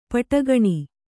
♪ paṭagaṇi